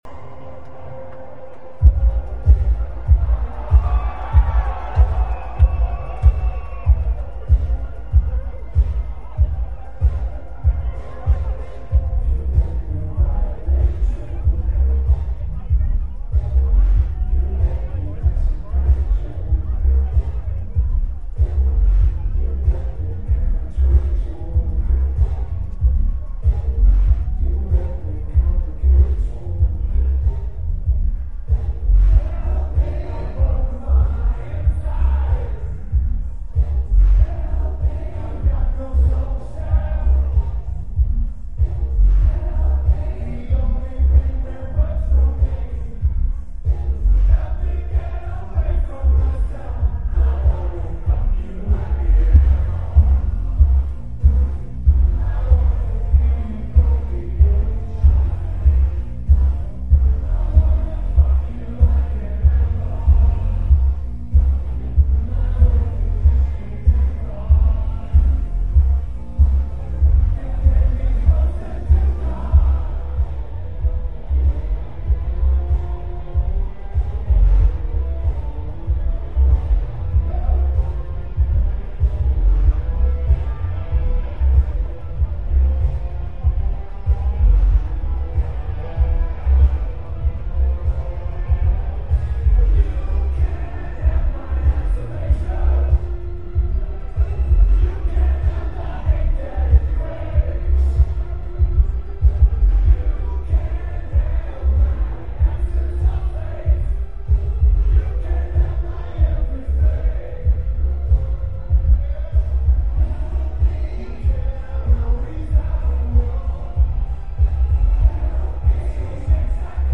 Civic Hall (Makeup from 03/12)